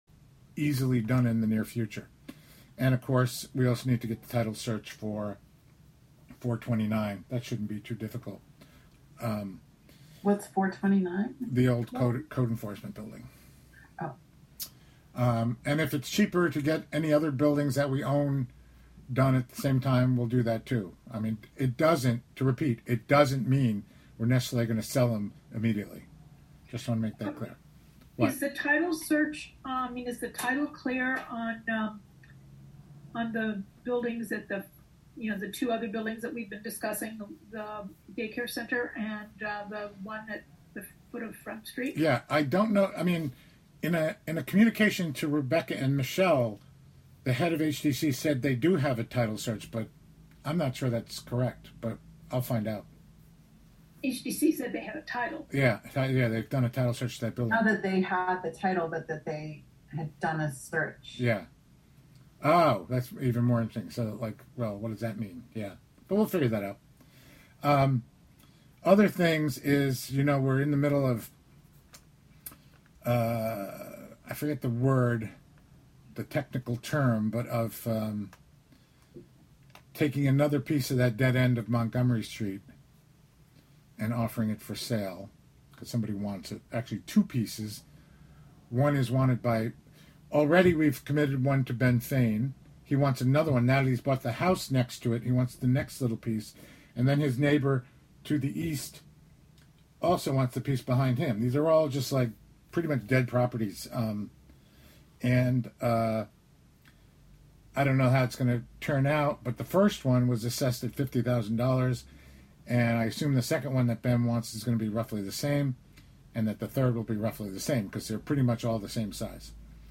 Live from the City of Hudson: Hudson Properties Committee (Audio)